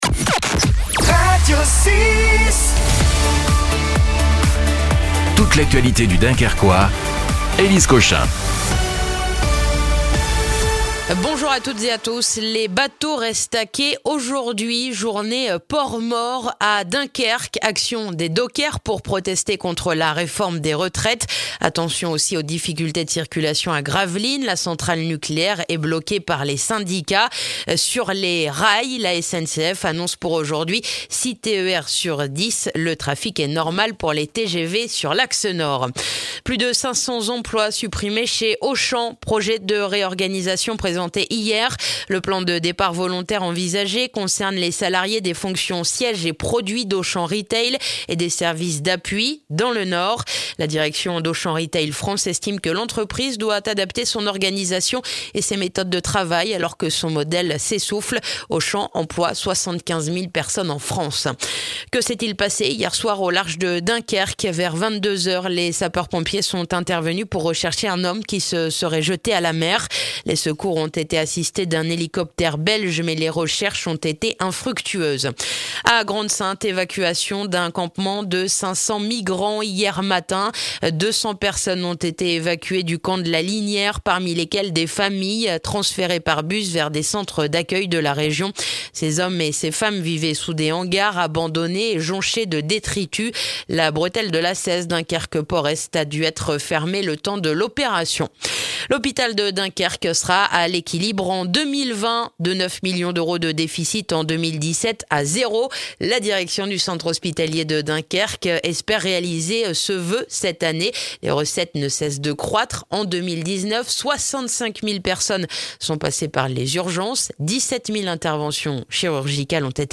Le journal du mercredi 15 janvier dans le dunkerquois